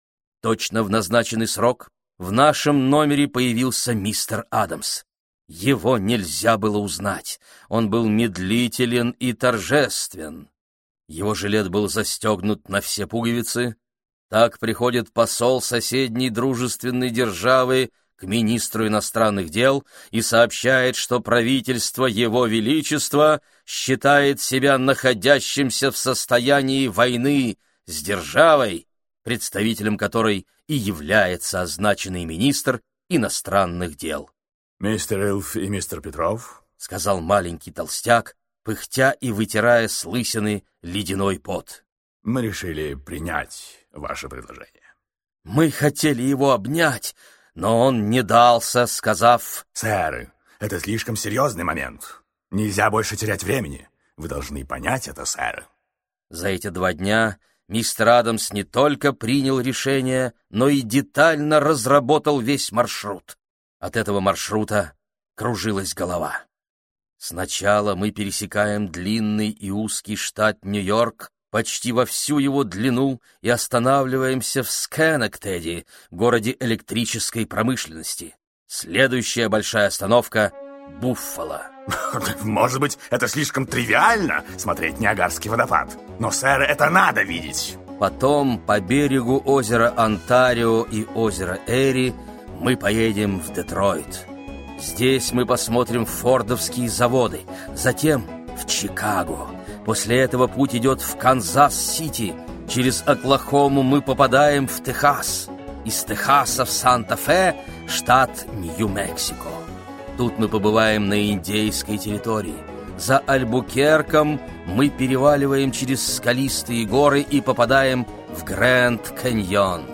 Аудиокнига Одноэтажная Америка | Библиотека аудиокниг